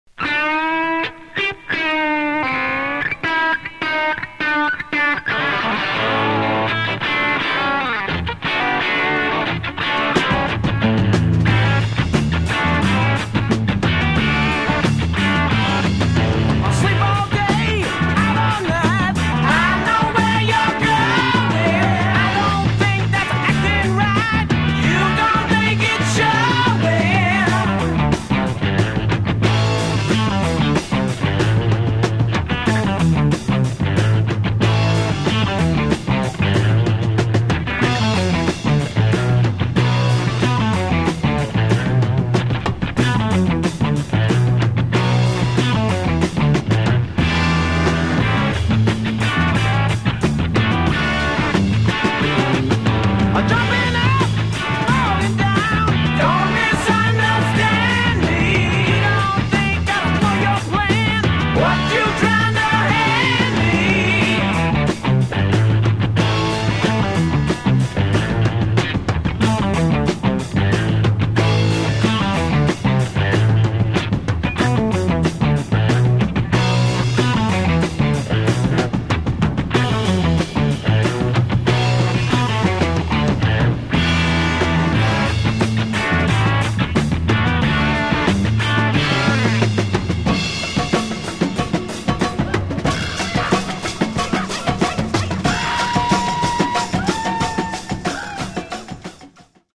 Genre: Hard Rock/Metal